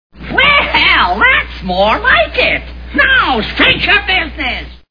The Wizard of Oz Movie Sound Bites